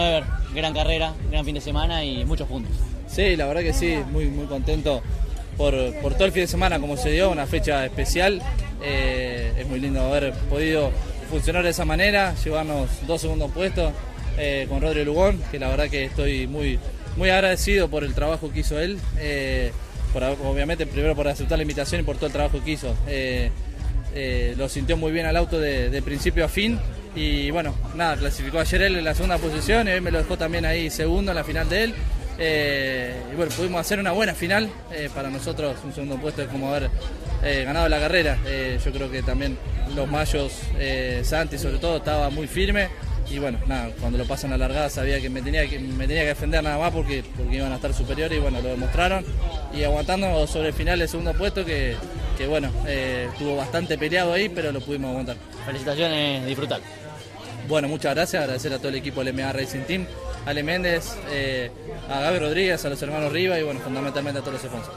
Estas son las respectivas entrevistas: